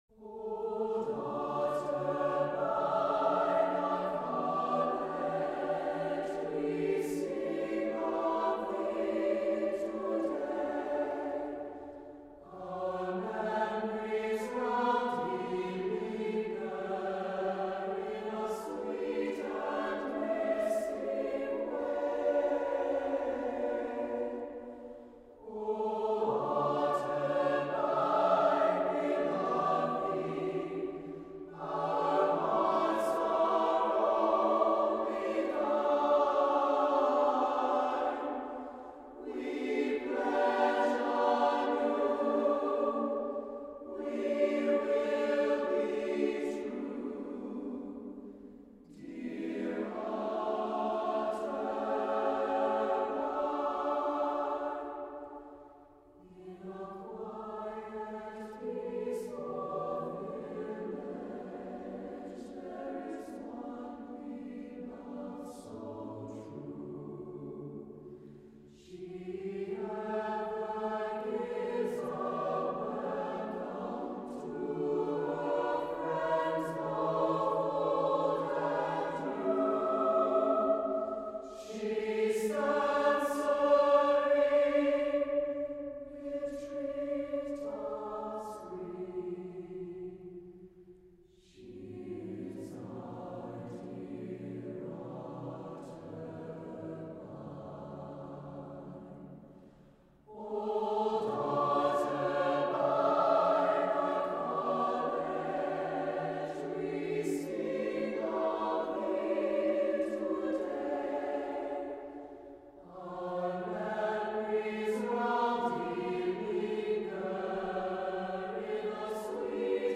Love-Song-Choir.mp3